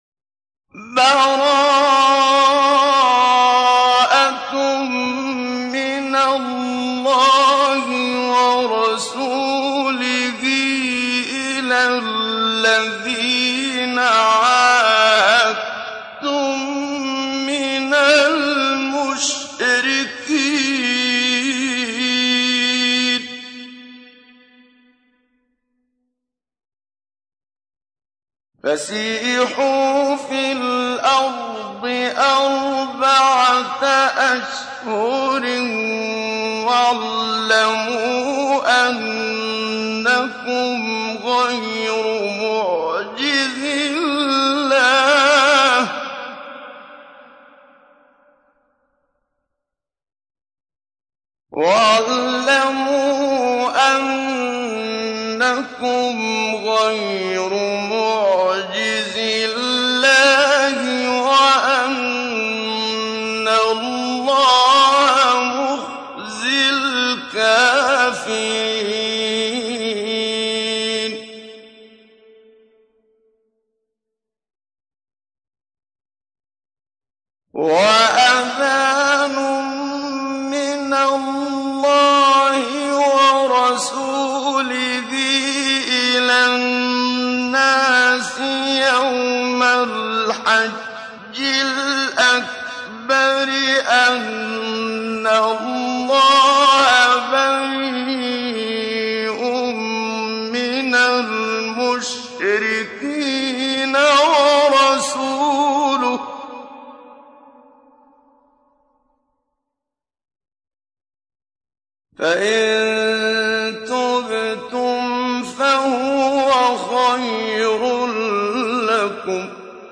تحميل : 9. سورة التوبة / القارئ محمد صديق المنشاوي / القرآن الكريم / موقع يا حسين